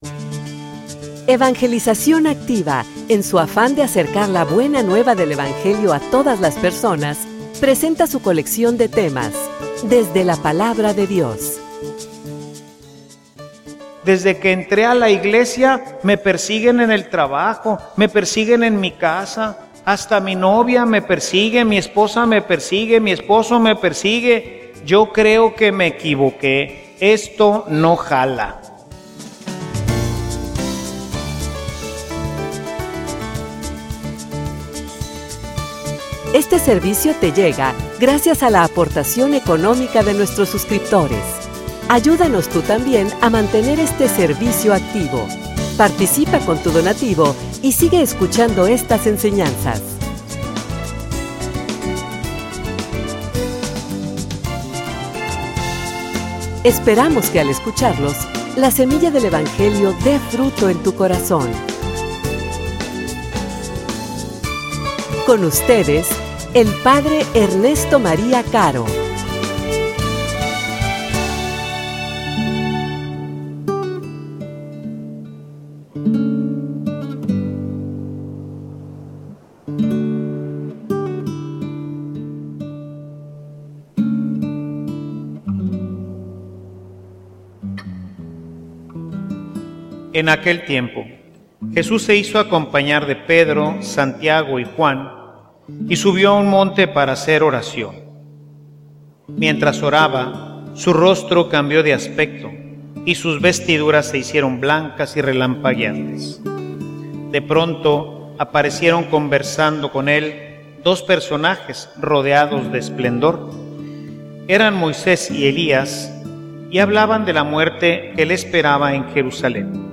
homilia_Una_fe_en_crisis.mp3